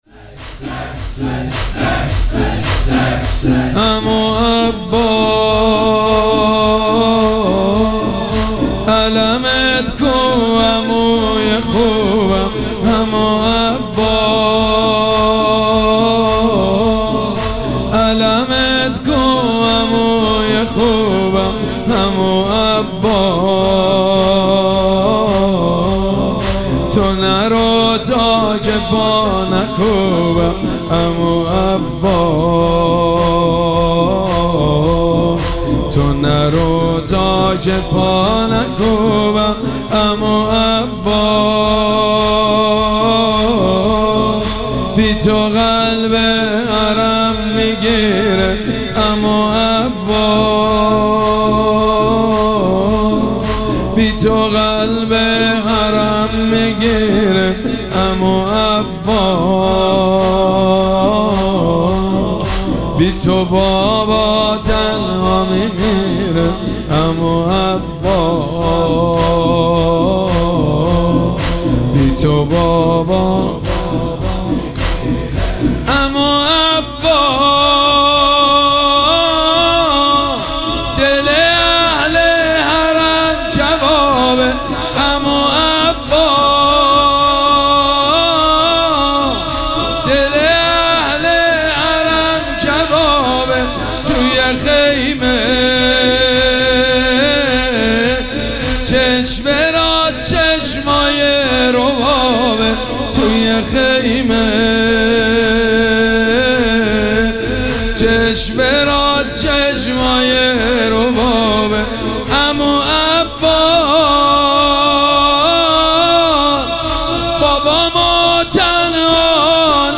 شب چهارم مراسم عزاداری اربعین حسینی ۱۴۴۷
شور
مداح
حاج سید مجید بنی فاطمه